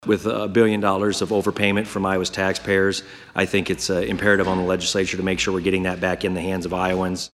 Grassley says tax conversations are going to be the most significant part of the 2022 session, as the state’s Taxpayer Relief Fund is overflowing.